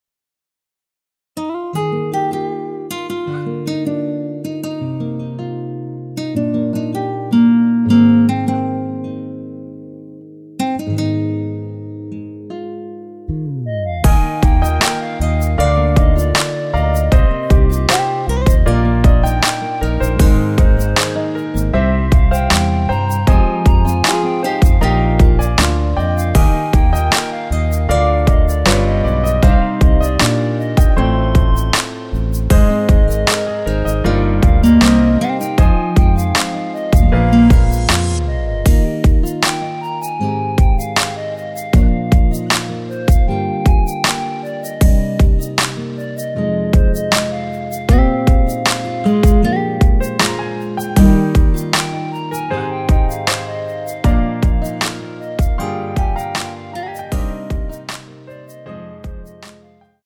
원키에서(-1)내린 멜로디 포함된 MR입니다.
Eb
앞부분30초, 뒷부분30초씩 편집해서 올려 드리고 있습니다.
중간에 음이 끈어지고 다시 나오는 이유는